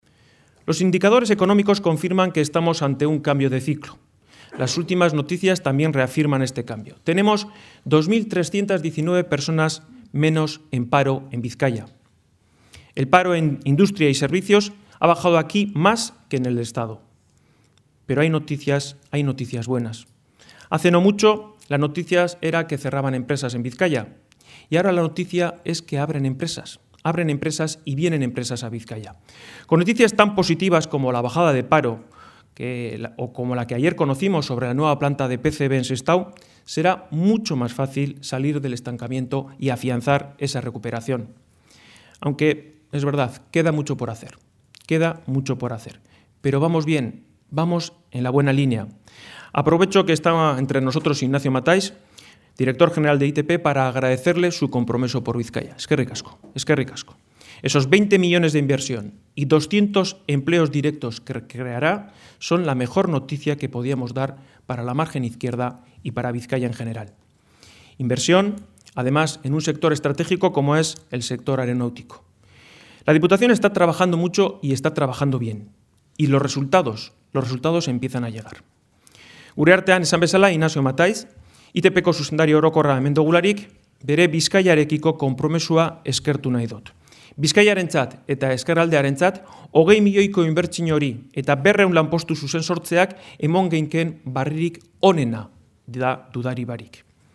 Unai Rementeria, candidato de EAJ-PNV a diputado general de Bizkaia, y Juan Mari Aburto, candidato jeltzale a la Alcaldía de Bilbao, han mantenido esta mañana en el hotel Meliá de Bilbao el último encuentro sectorial en el que han presentado ante una veintena de representantes empresariales y del sector económico del territorio y la capital sus propuestas en materia de reactivación económica y generación de empleo.